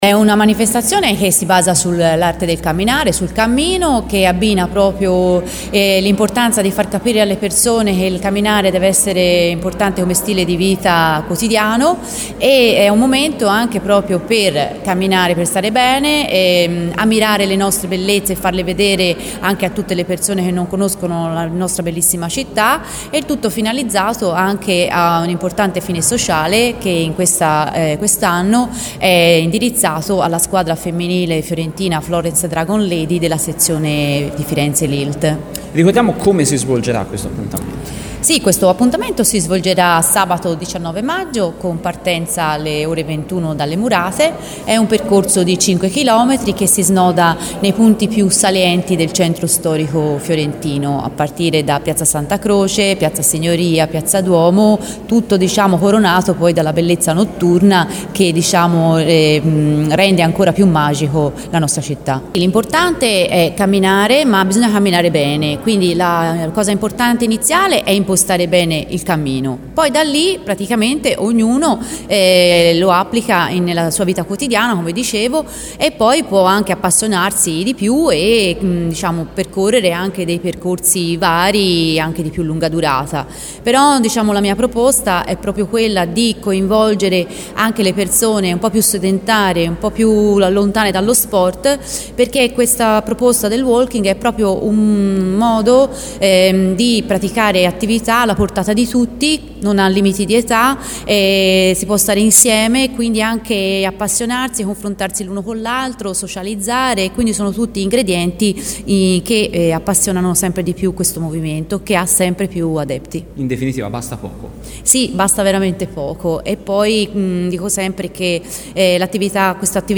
Intervista del 16 maggio a Radio Toscana sul Florence Walking by Night